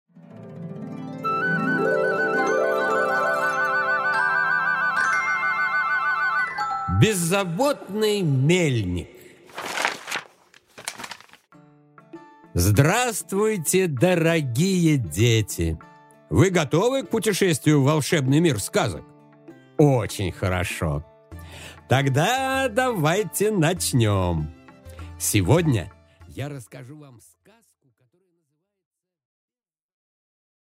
Аудиокнига Беззаботный мельник